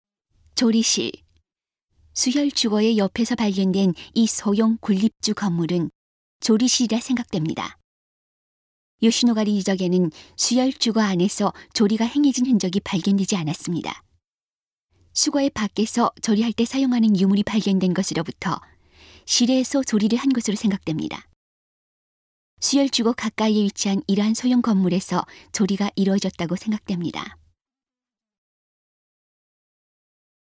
수혈주거 가까이에 위치한 이 소형건물에서 조리가 이루어졌다고 추정됩니다. 음성 가이드 이전 페이지 다음 페이지 휴대전화 가이드 처음으로 (C)YOSHINOGARI HISTORICAL PARK